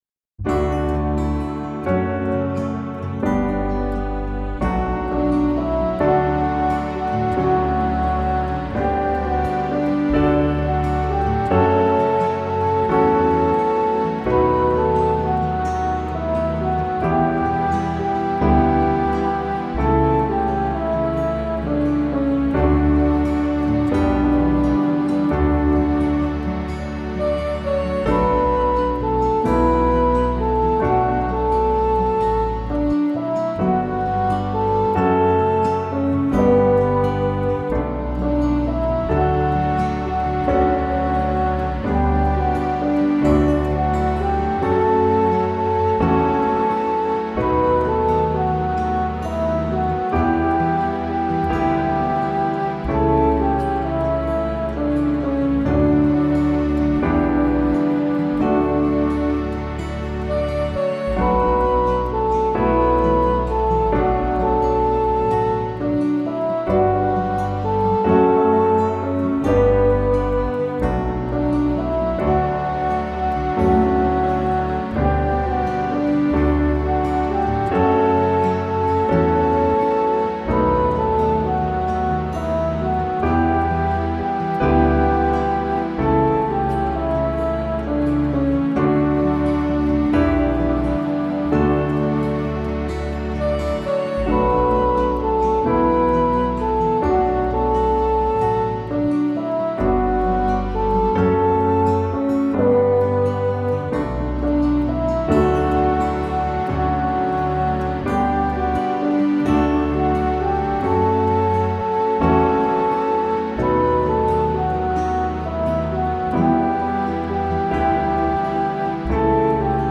It is a nice short hymn.